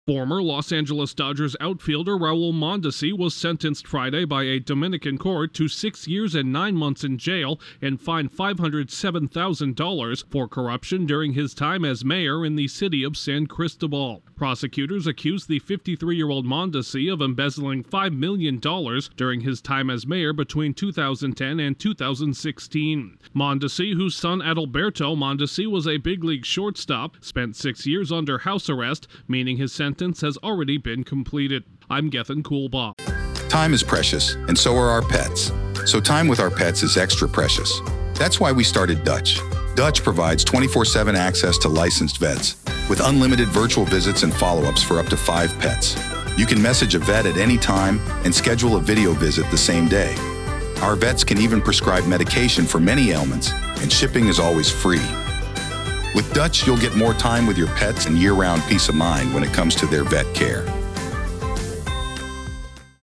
A former MLB All-Star has been sentenced to nearly seven years in jail in his native Dominican Republic. Correspondent